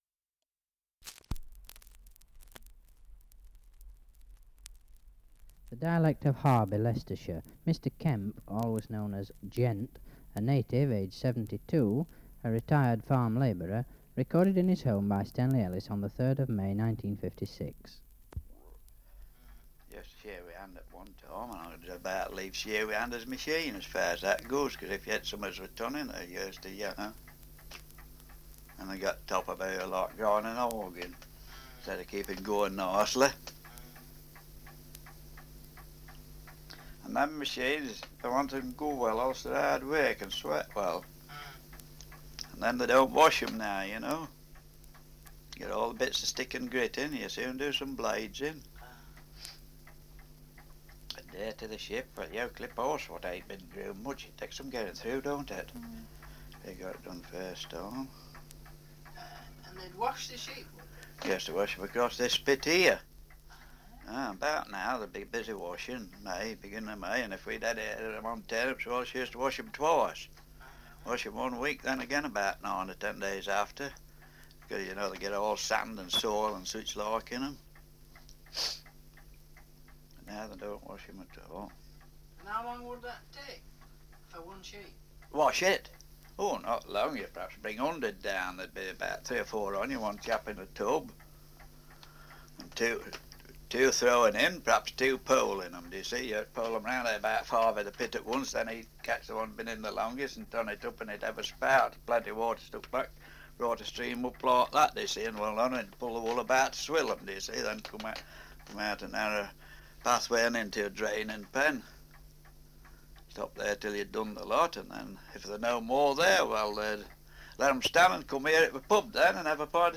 Survey of English Dialects recording in Harby, Leicestershire
78 r.p.m., cellulose nitrate on aluminium